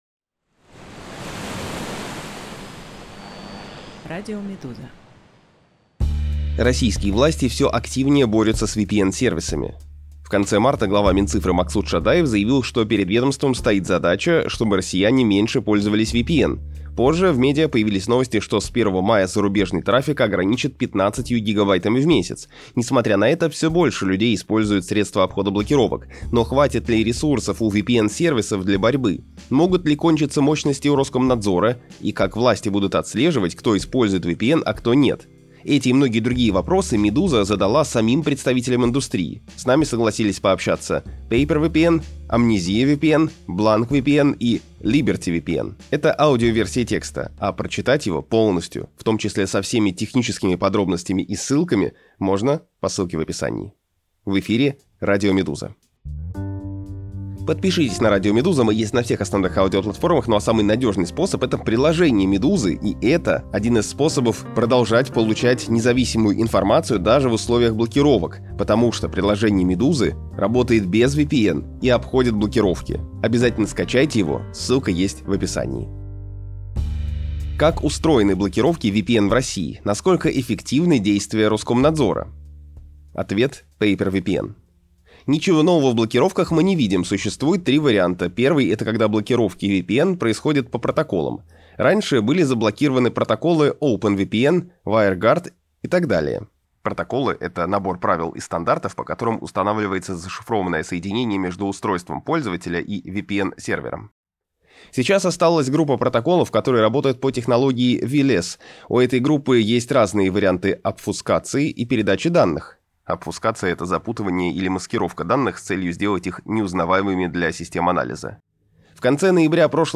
Оглавление Аудиоверсию этого текста слушайте на «Радио Медуза» подкасты Можно ли действительно заблокировать все VPN?